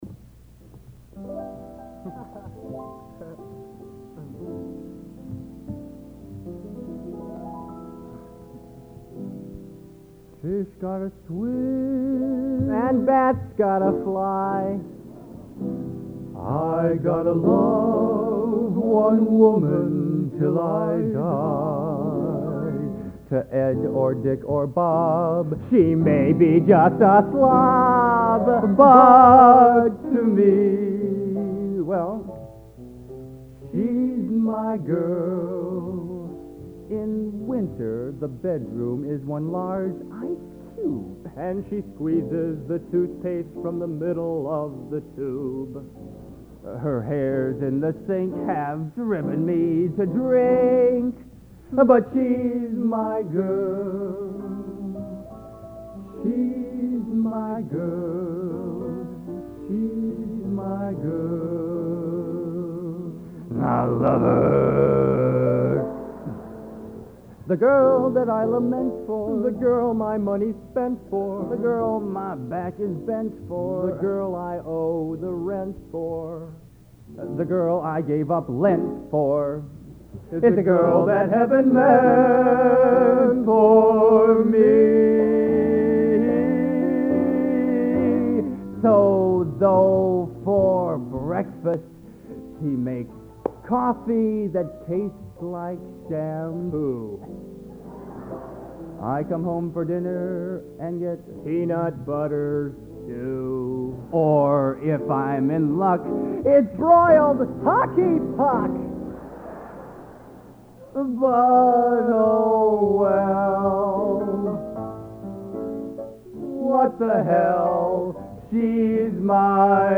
Location: West Lafayette, Indiana
Genre: | Type: End of Season |Featuring Hall of Famer